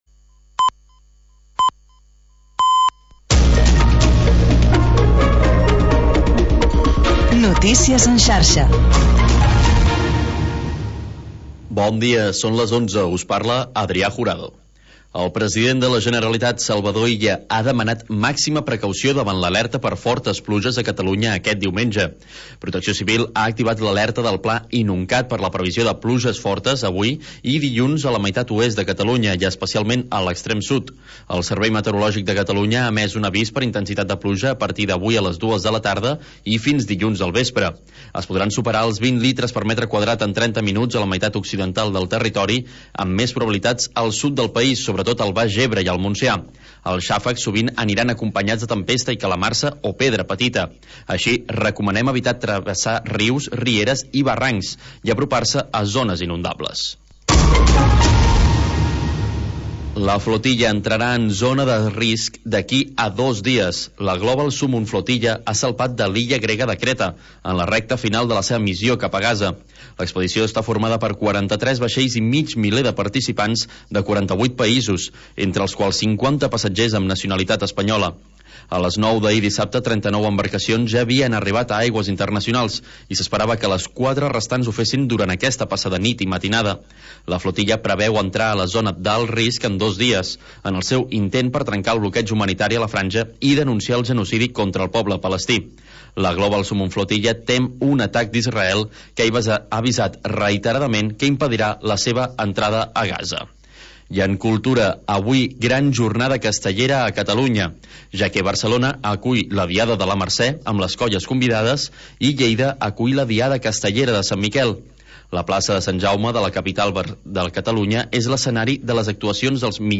Programa amb format de magazín amb seccions com Notícies, Agrupats, Compositors entre d'altres.